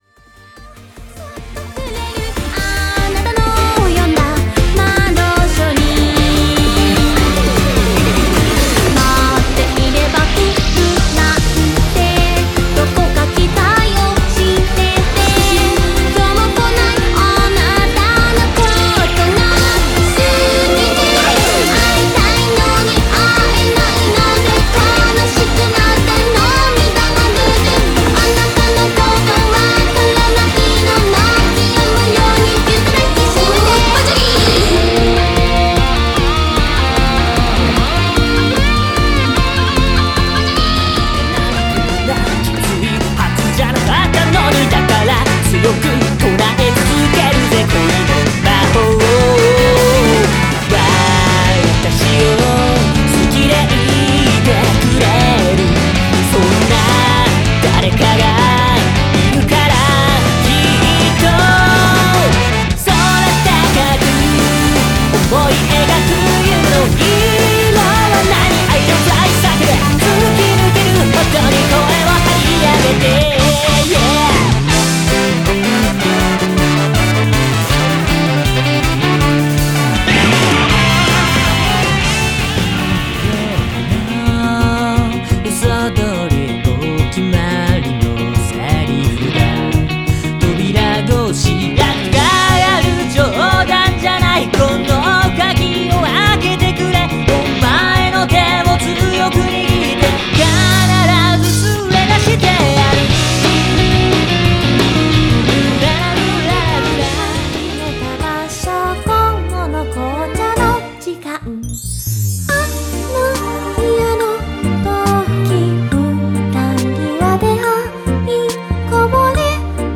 クロスフェードDEMO（Tr.01〜05）